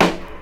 • Nice Steel Snare Drum Sample F Key 106.wav
Royality free steel snare drum sample tuned to the F note. Loudest frequency: 1252Hz
nice-steel-snare-drum-sample-f-key-106-S3g.wav